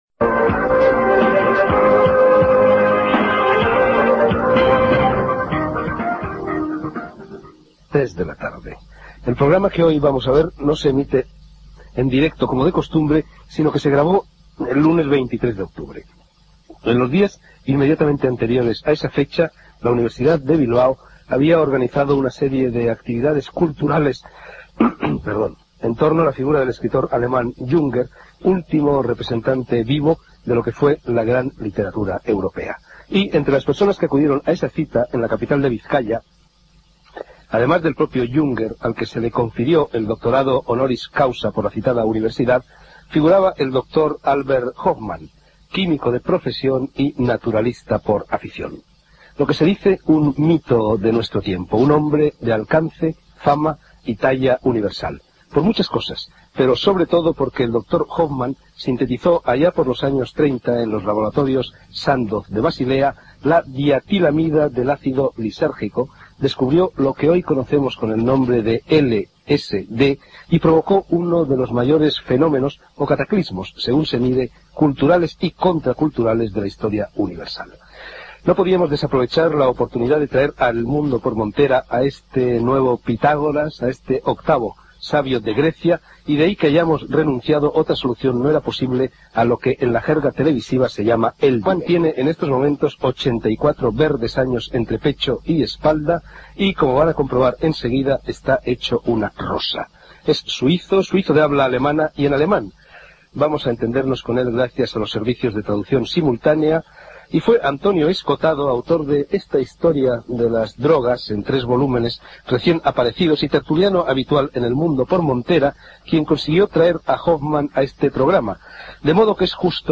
Hora, presentacio i entrevista al químic Albert Hofmann, feta a Bilbao